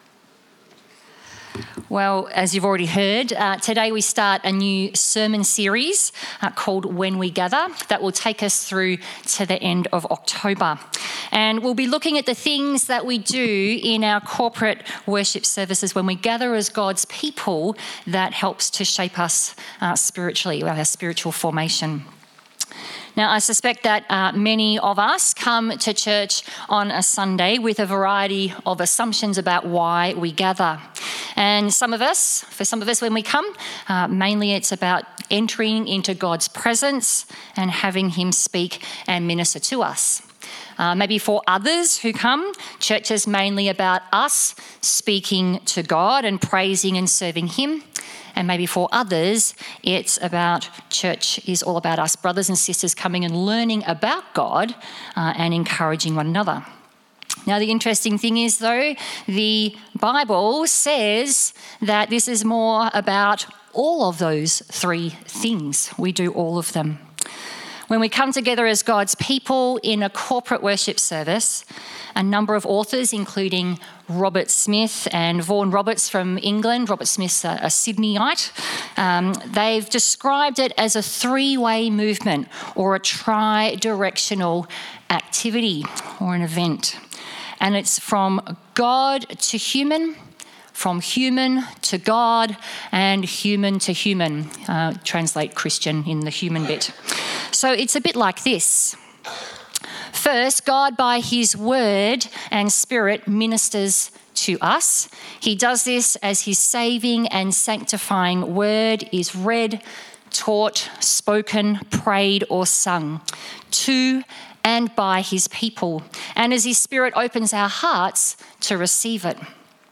Sermons | St Alfred's Anglican Church
The bible reading is Psalm 147:1-7, Colossians 3:15-17.